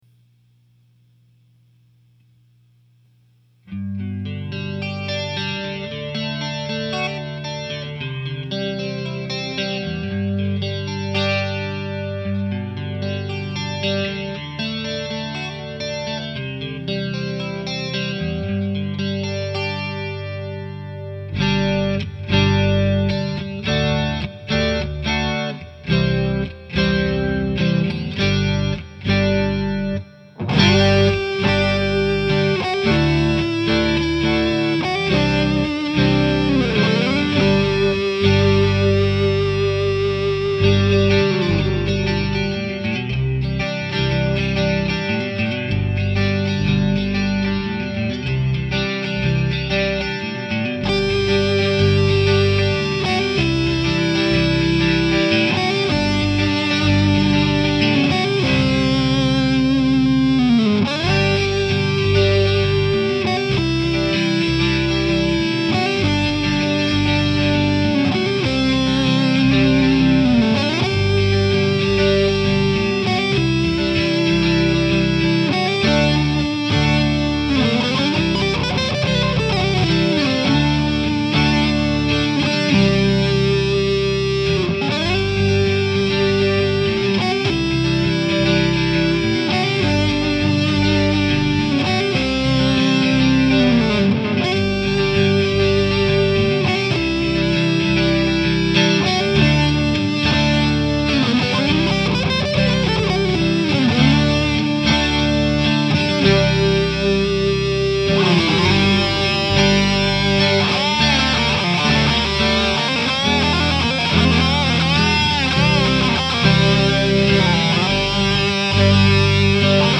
• Drums – Boss, Dr. Rhythm Drum Machine
• Recorded at the Park Springs Recording Studio